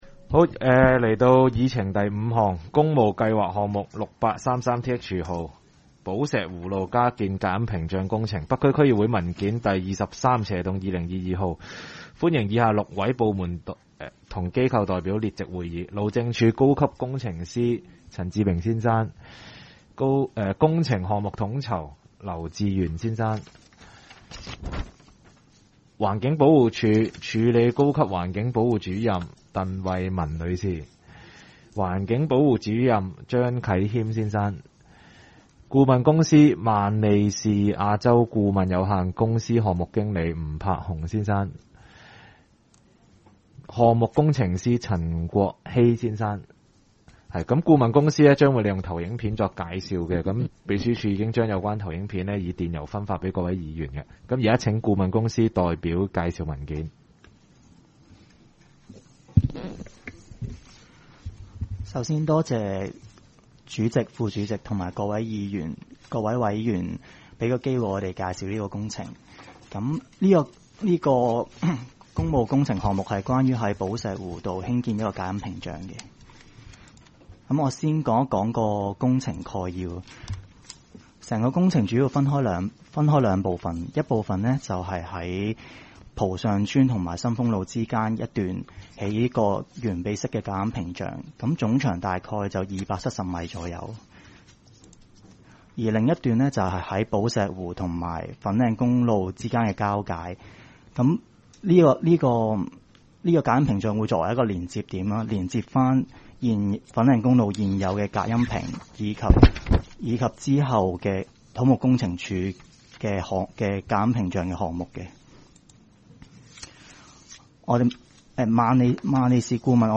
区议会大会的录音记录
北区区议会第十二次会议
北区民政事务处会议室